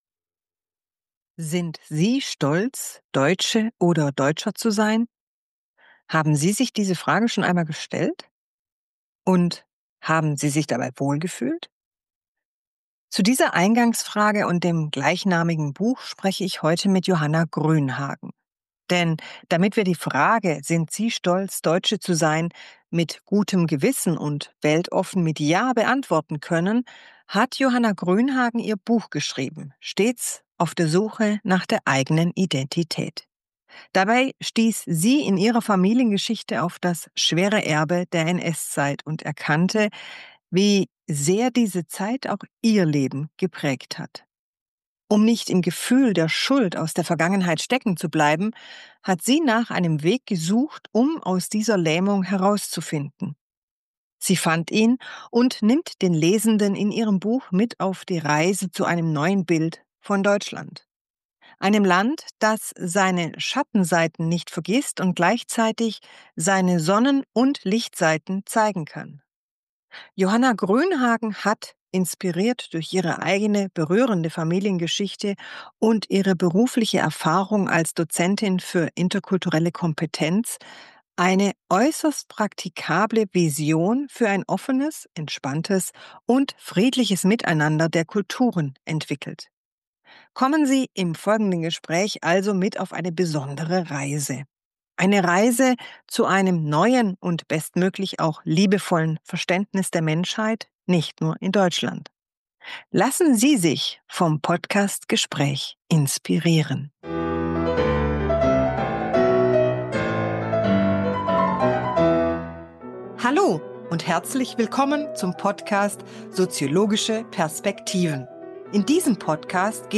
Kommen Sie im folgenden Gespräch also mit auf eine besondere Reise.